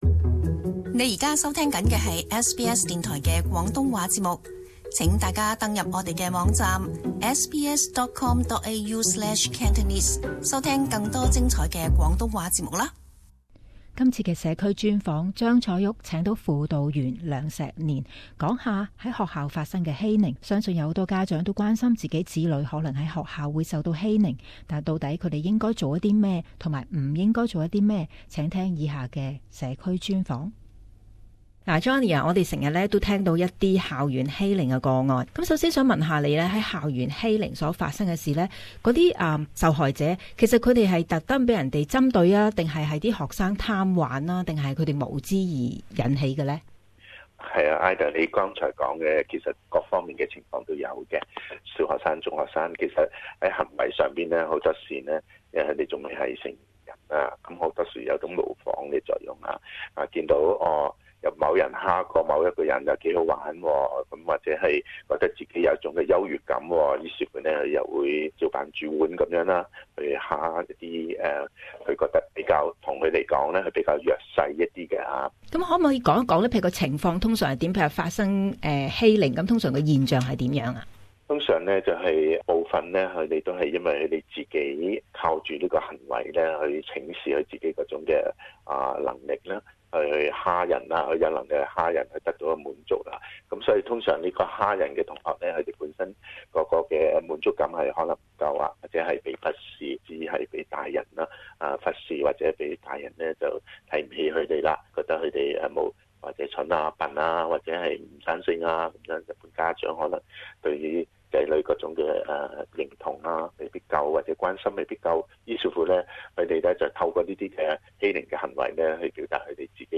【社區專訪】學校欺凌